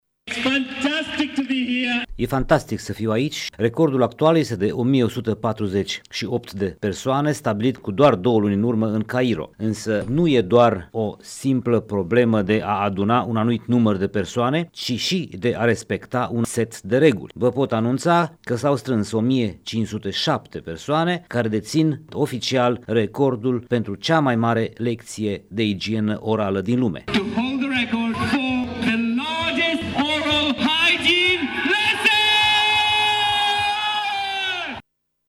a anunțat, în entuziasmul celor prezenți care au înfruntat ploaia torențială, că recordul a fost doborît :